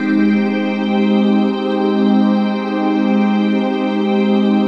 DM PAD2-74.wav